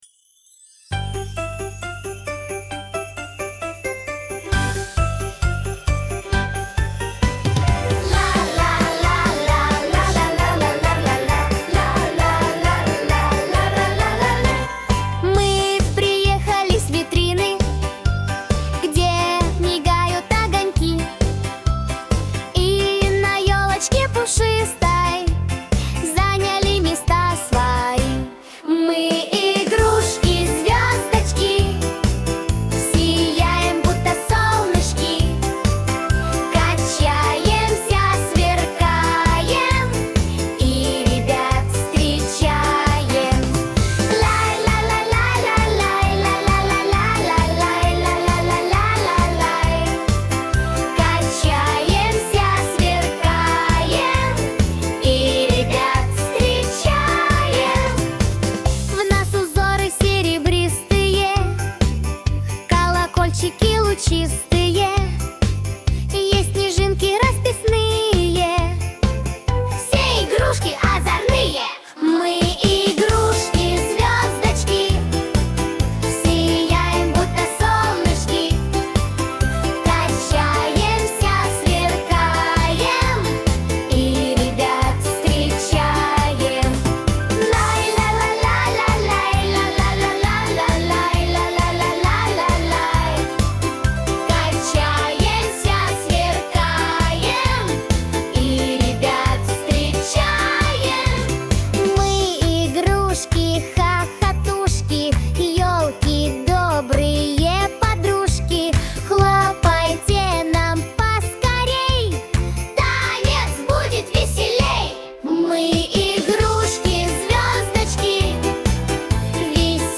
• Категория: Детские песни
новогодняя дискотека